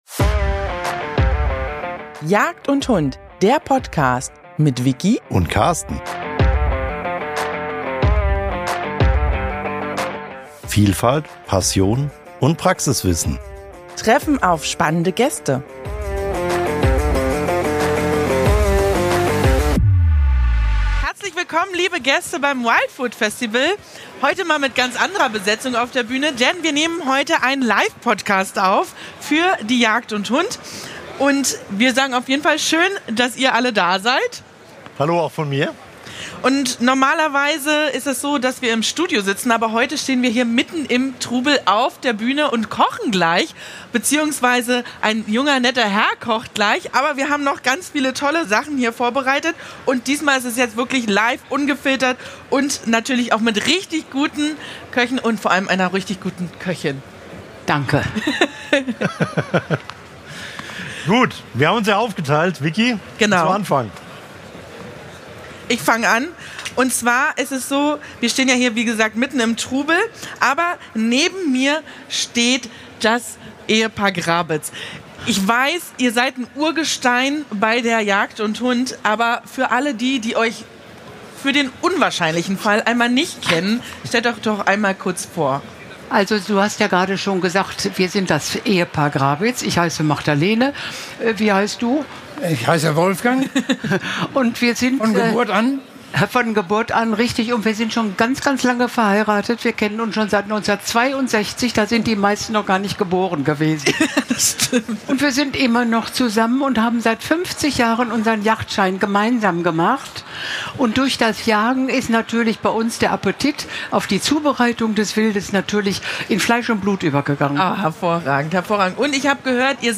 Mitten im Messetrubel, vor Publikum und mit laufender Pfanne entsteht ein Podcast, der man nicht nur hört, sondern fast riechen und schmecken kann.